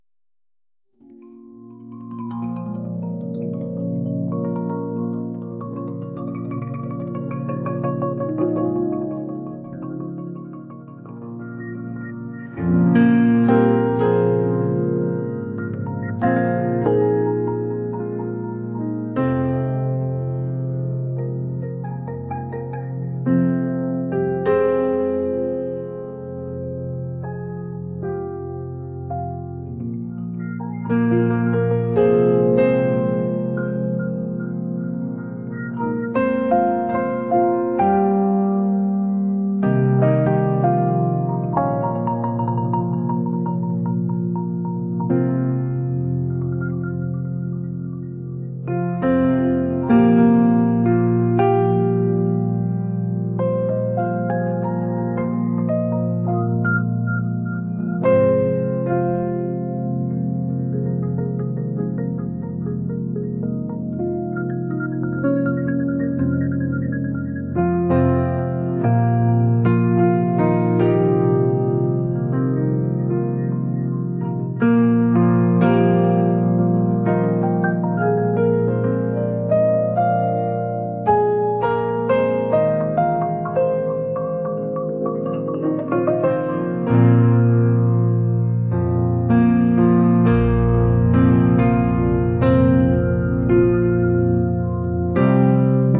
不気味